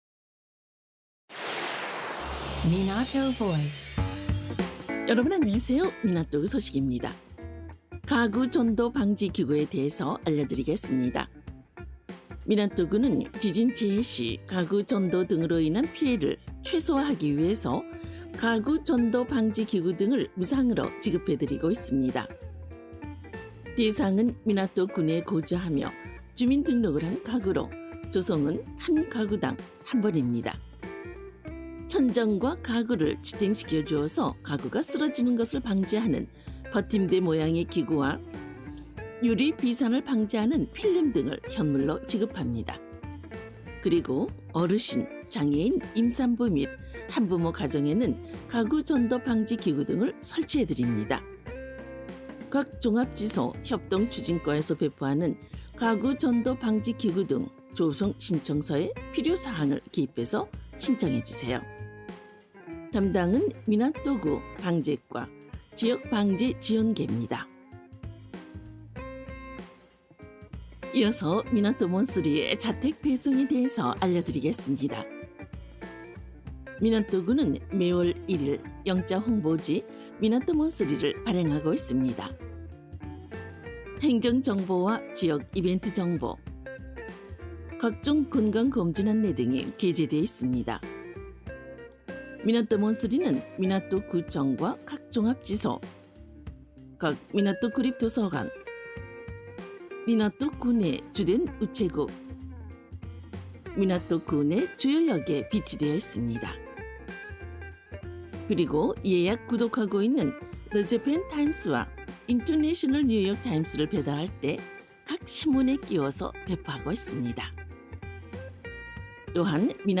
(Audio) 2022년 1월 28일 방송 ‘가구 전도 방지기구 조성/ Minato Monthly 자택 배송’